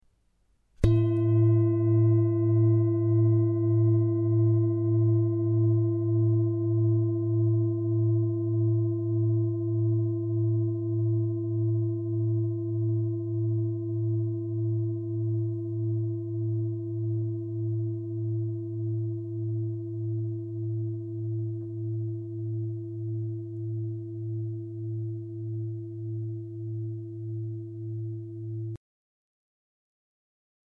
Indische Bengalen Klangschale - MOND SIDERISCHER MONAT
Grundton: 113,12 Hz
1. Oberton: 338,80 Hz
MONDTON SIDERISCHER MONAT